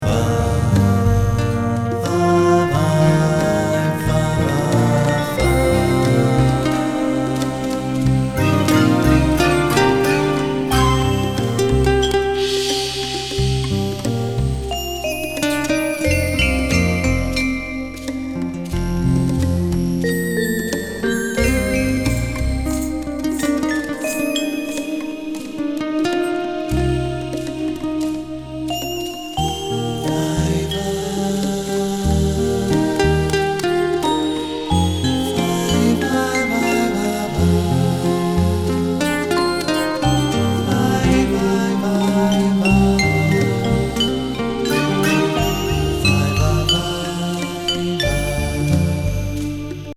空間に溶けるエレクトロニクスとボーカルで失禁級の